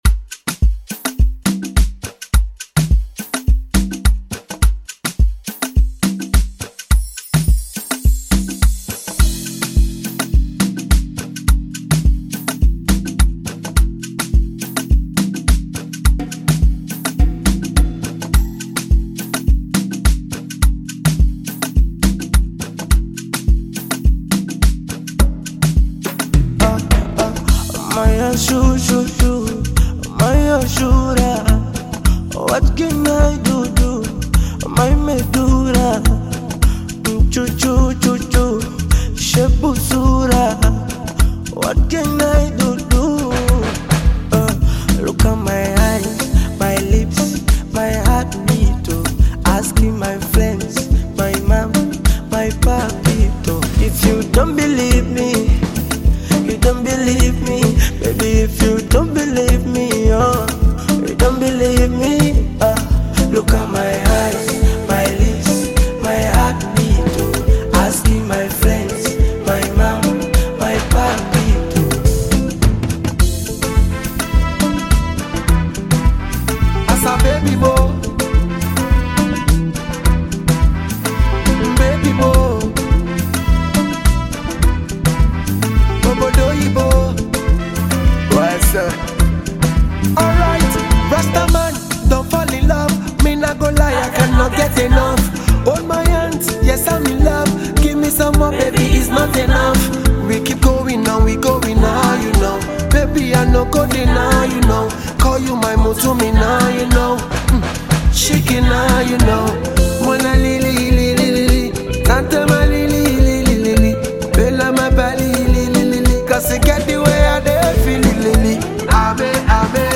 Bongo Flava music track
This catchy new song